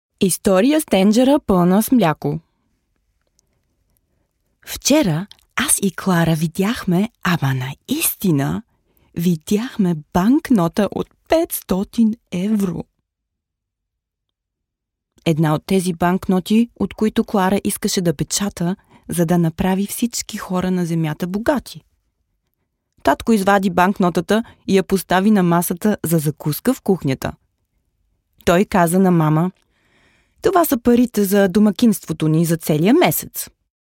Voiceovers
11346-bulgarian-female-other.mp3